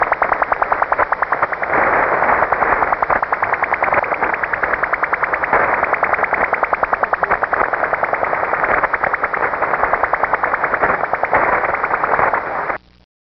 Over-The-Horizon_Radar_Military.mp3